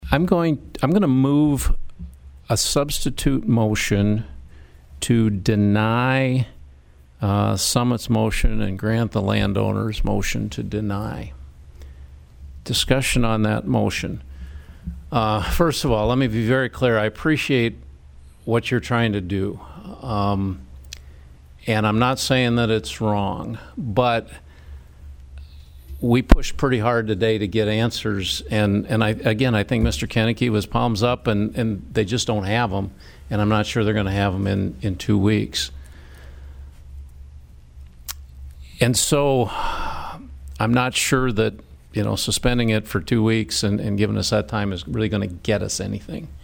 PIERRE, S.D.(HubCityRadio)- The South Dakota Public Utilities Commission held their meeting Thursday in Pierre.